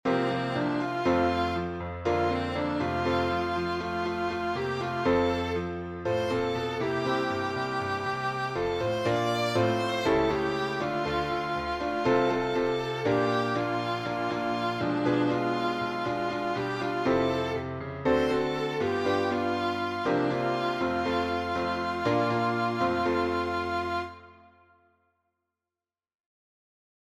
Zipper Song